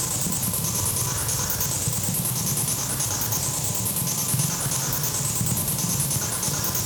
Index of /musicradar/stereo-toolkit-samples/Tempo Loops/140bpm
STK_MovingNoiseE-140_02.wav